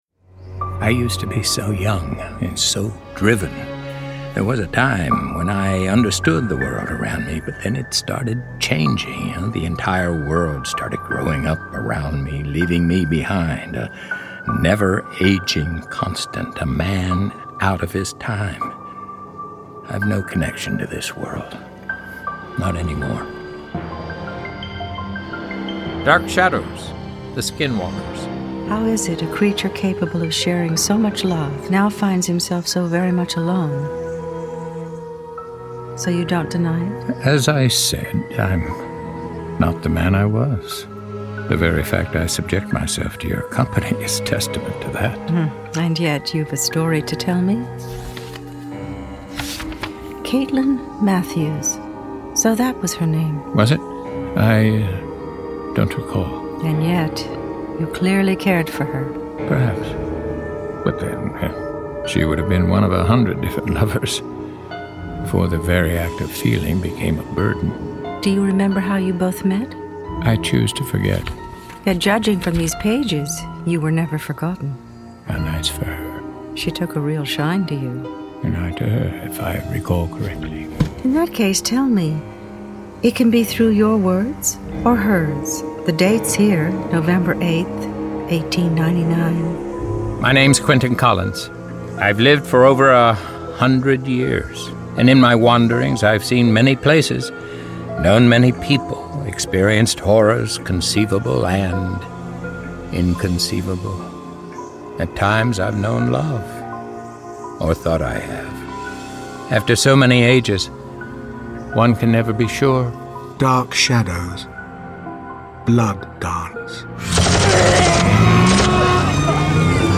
Dark Shadows: The Quentin Collins Collection - Dark Shadows - Dramatised Readings - Big Finish
Starring David Selby